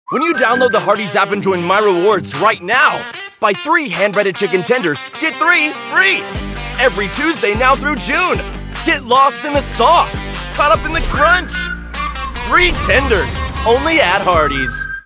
Youthful, Gen Z, energetic male voice with a natural, conversational tone.
Television Spots
Hardees Fast Food Tv Commercial
Words that describe my voice are Conversational, Young, Energetic.